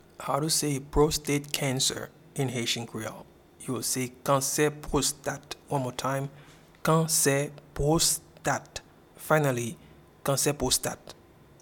Pronunciation and Transcript:
Prostate-cancer-in-Haitian-Creole-Kanse-pwostat.mp3